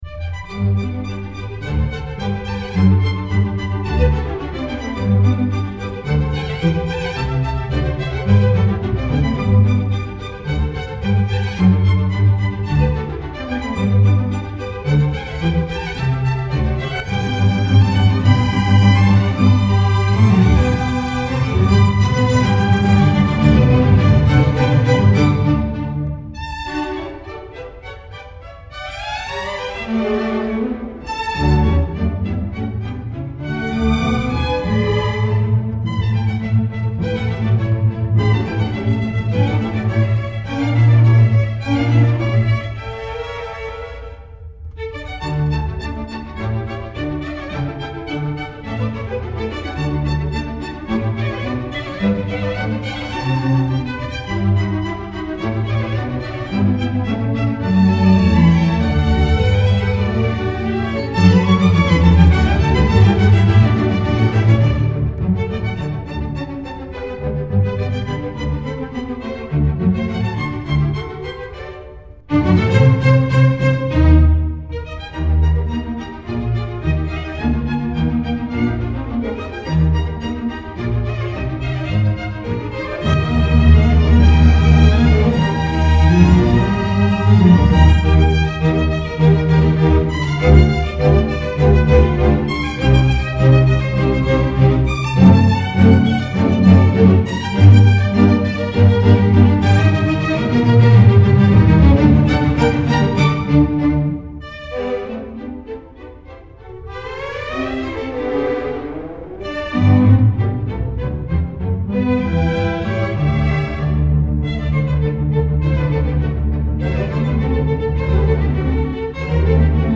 MOZART, Rondo, allegro - HOGARTH, the Graham children.mp3